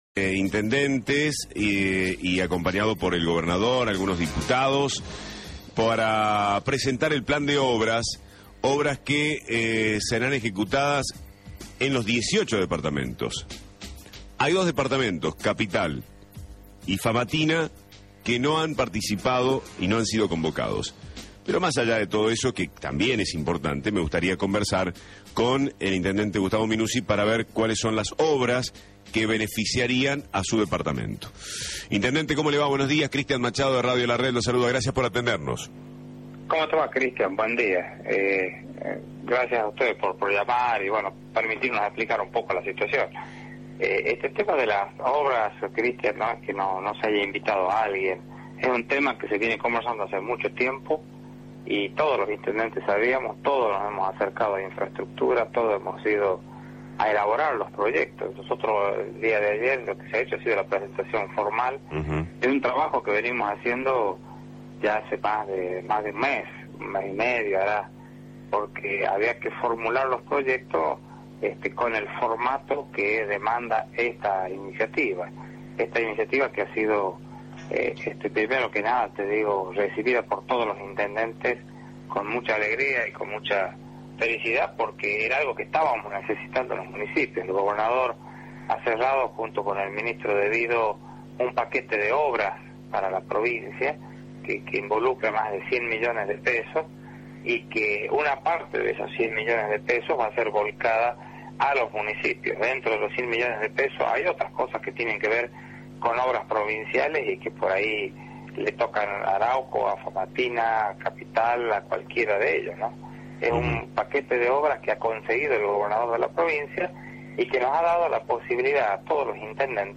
Minuzzi habló en la mañana del jueves desde Buenos Aires por Radio La Red, tras participar de la presentación del plan de obra pública en el Ministerio de Planificación Federal, que encabeza Julio De Vido.
gustavo-minuzzi-intendente-de-arauco-por-radio-la-red.mp3